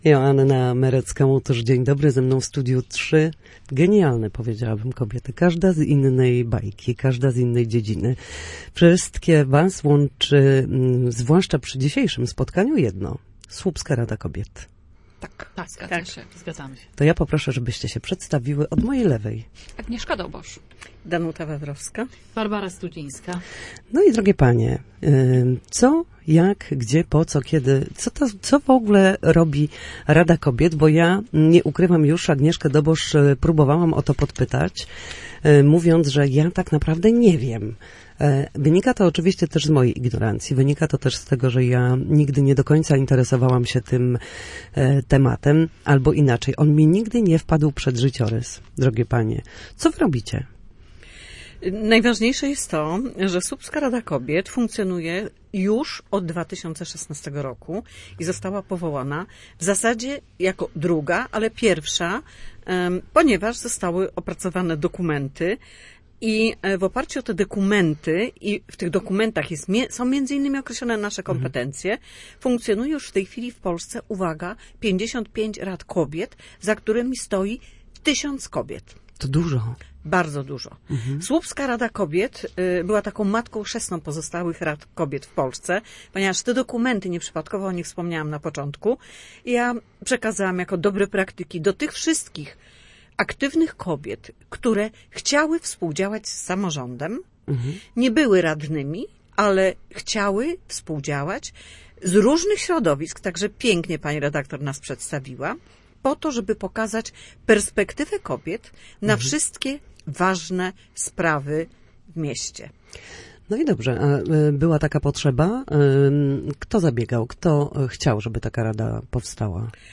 na naszej antenie mówiły o potrzebie powołania rad kobiet,